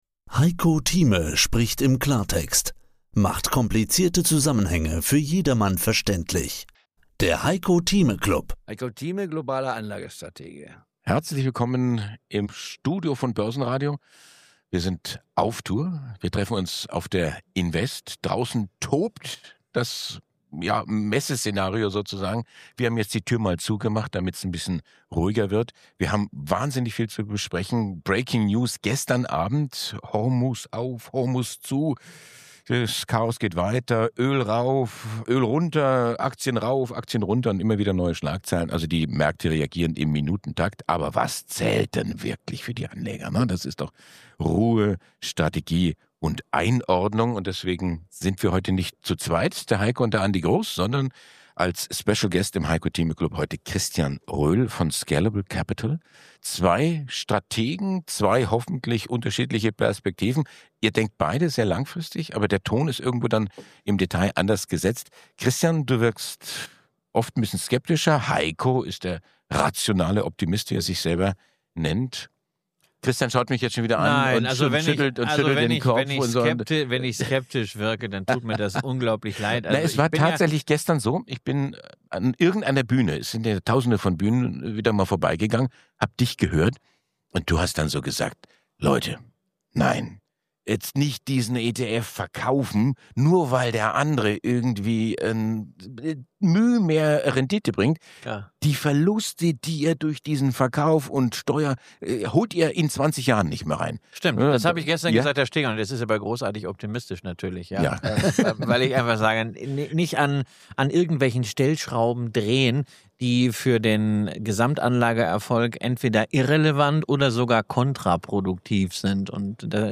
Live von der INVEST in Stuttgart: